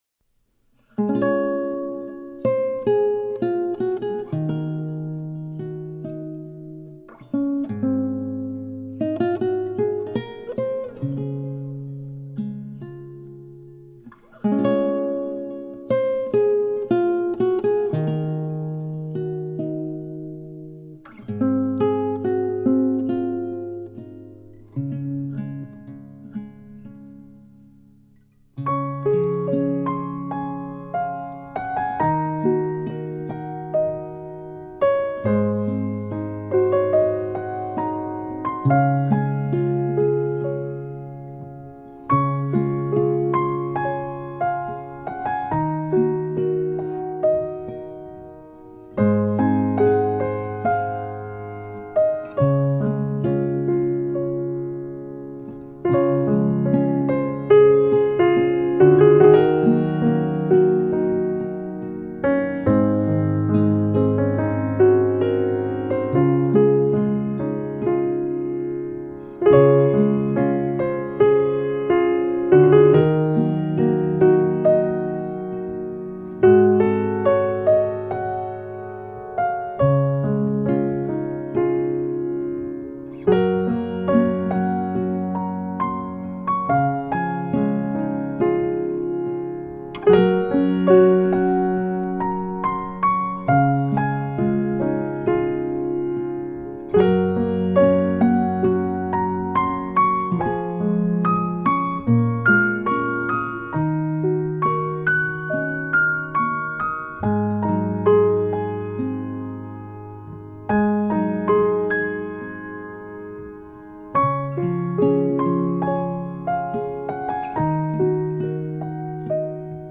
鋼琴
部分曲子加入弦樂、吉他、手風琴等樂器，呈現更豐富的音樂氛圍。
用最溫柔、平和的曲調表現出來。